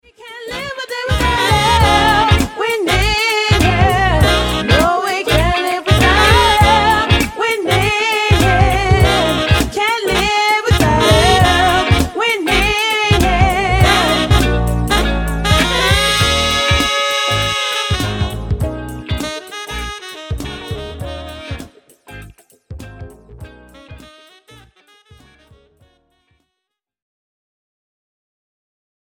Style: Jazz